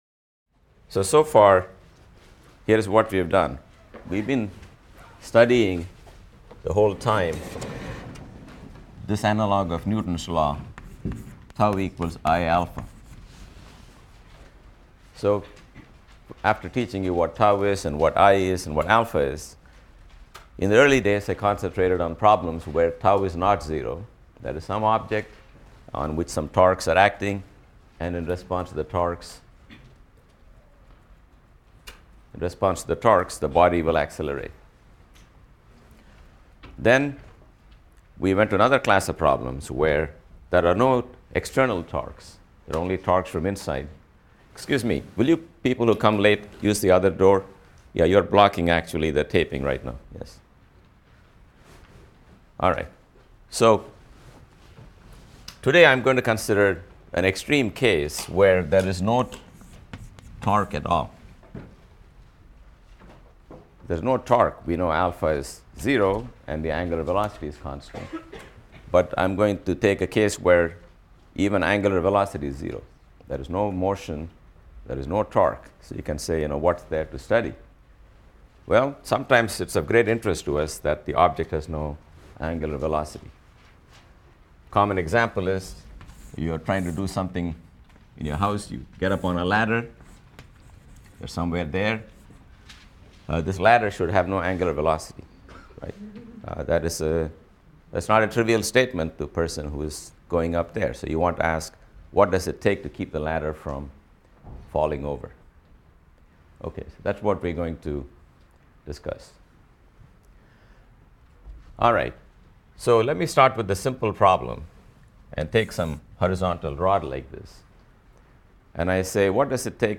PHYS 200 - Lecture 11 - Torque | Open Yale Courses